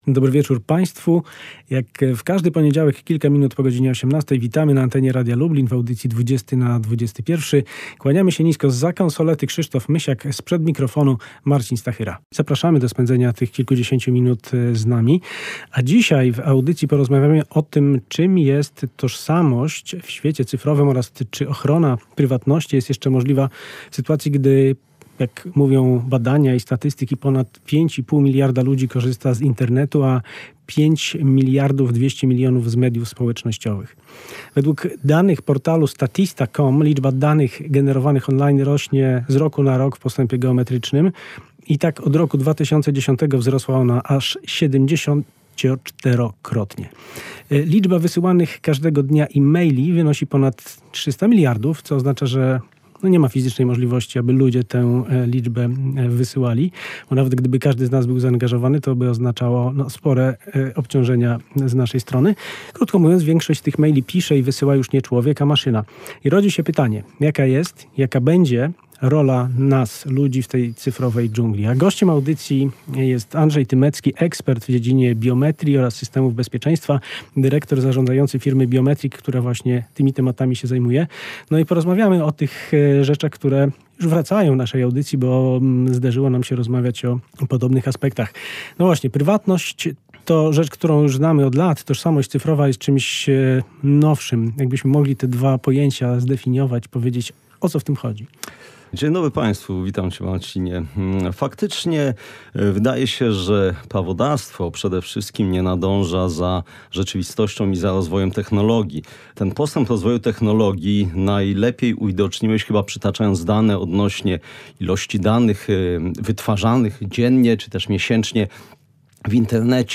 Muzyczni towarzysze z różnych dekad zapewnią nam ścieżkę dźwiękową, w której dominować będzie poczucie niepokoju.